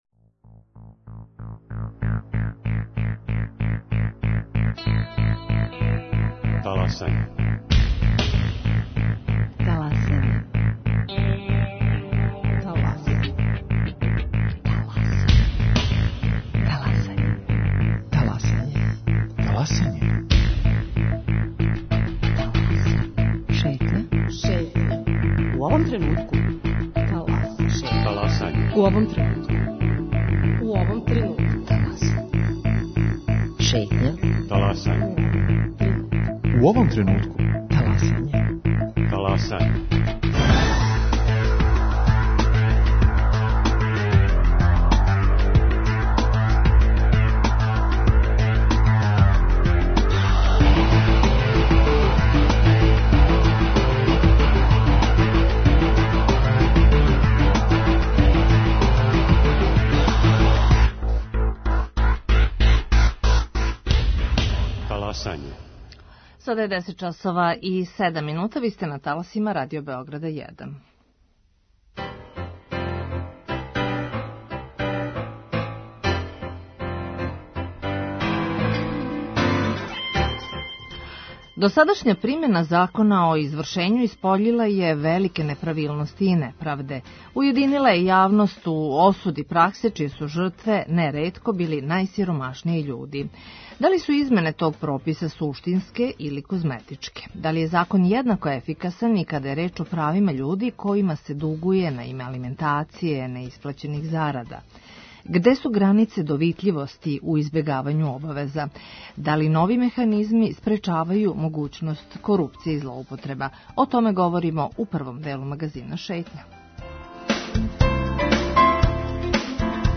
У репортажи из села Врмџа на планини Ртањ чућемо искуства људи који су своју егзистенцију и срећу остварили напустивши Београд.